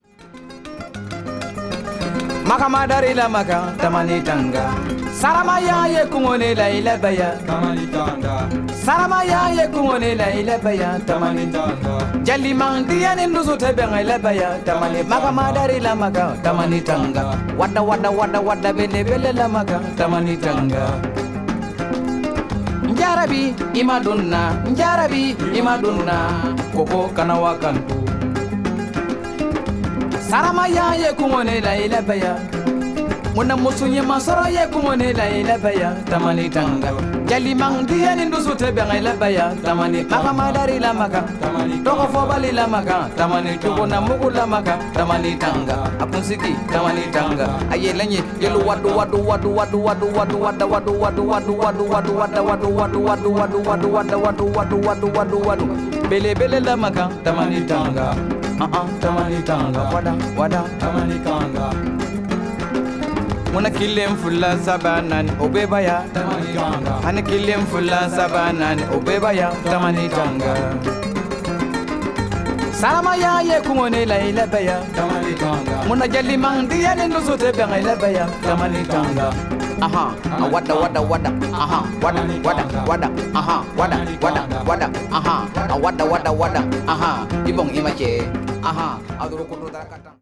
africa   folklore   griot   traditional   world music